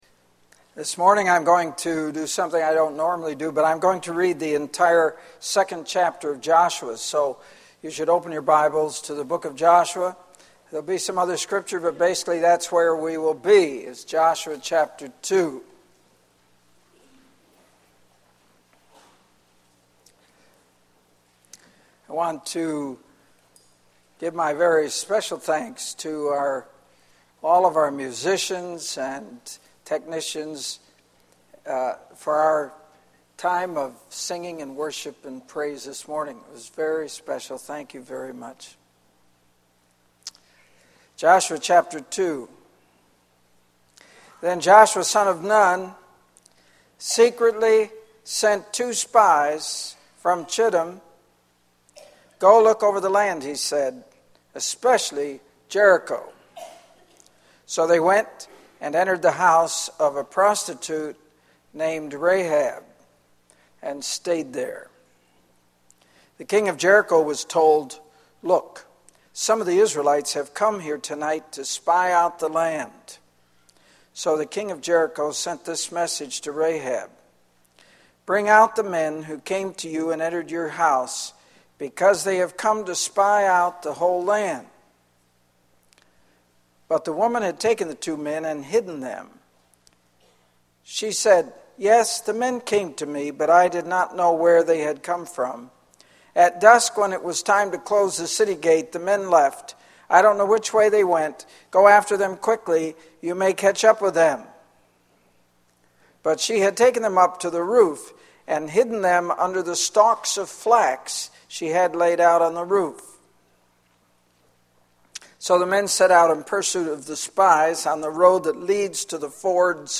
The sermon calls for listeners to acknowledge their need for salvation and to embrace the grace offered through Christ, just as Rahab did for herself and her family.